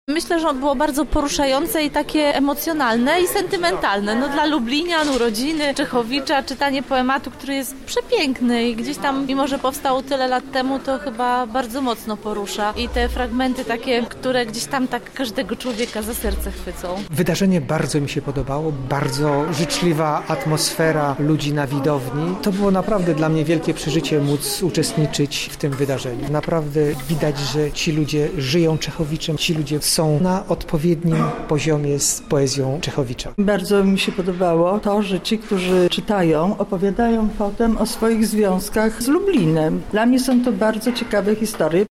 zapytał uczestników o wrażenia po spotkaniu.